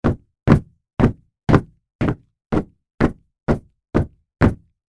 Звуки сапог
Тяжелые шаги по деревянным ступеням или чердачным доскам в солдатских сапогах